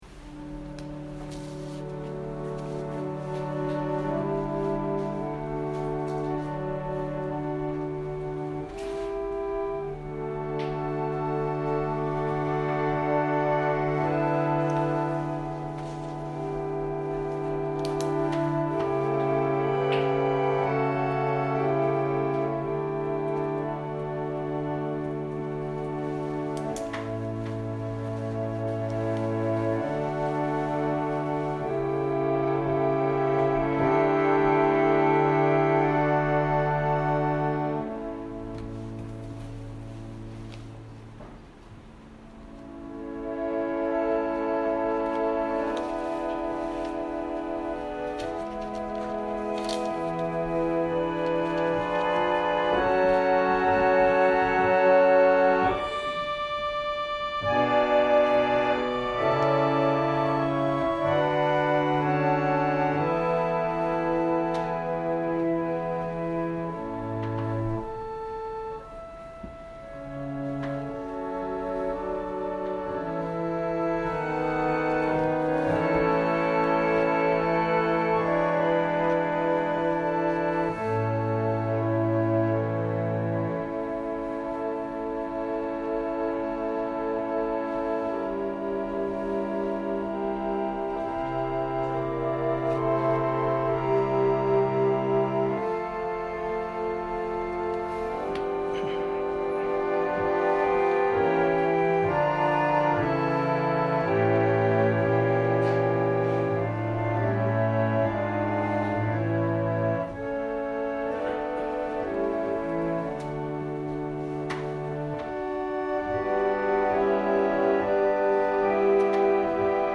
音声ファイル 礼拝説教を録音した音声ファイルを公開しています。